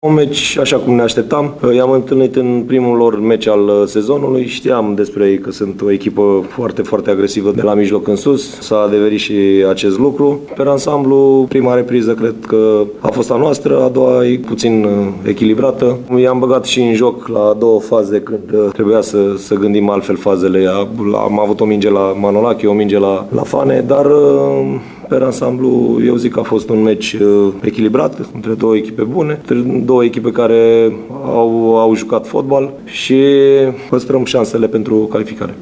La final, antrenorul Corvinului